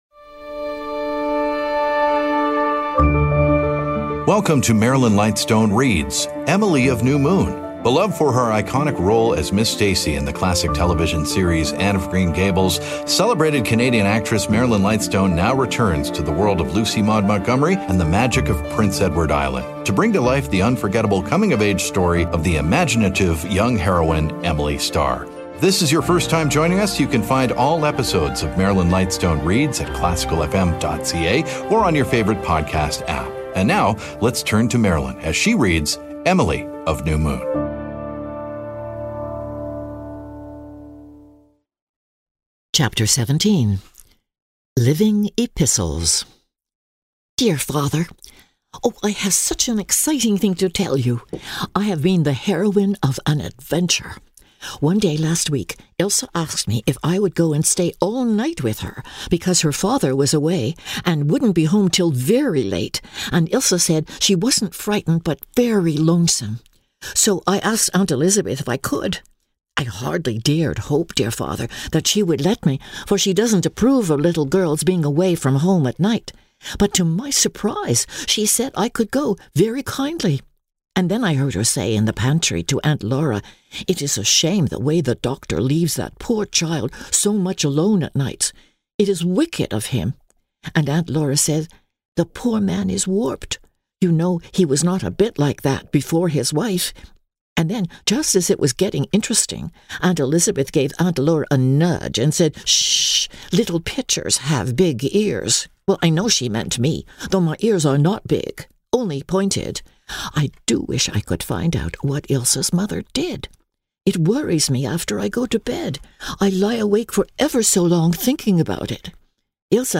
Emily Of New Moon: Chapters 17-18 Marilyn Lightstone Reads podcast
Theater Arts Marilyn Lightstone Zoomer Podcast Network Society Audio Drama Vanity Fair Content provided by Marilyn Lightstone and Zoomer Podcast Network.